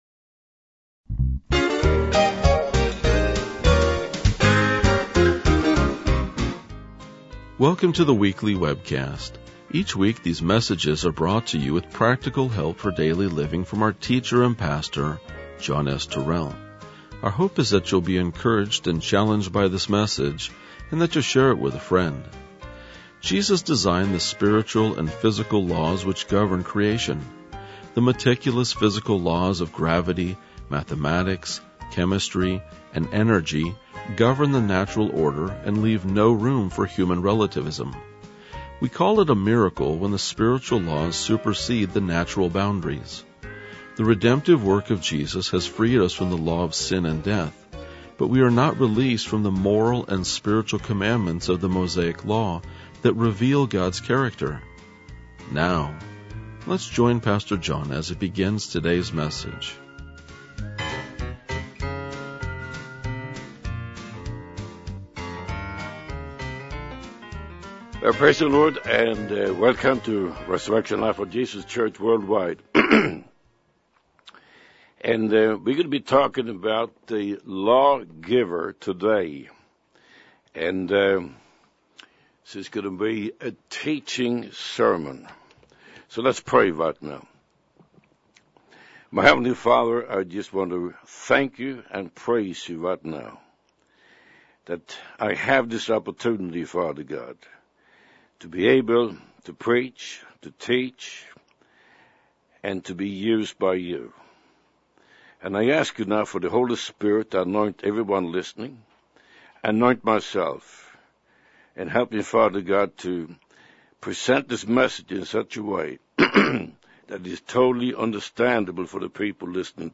RLJ-2038-Sermon.mp3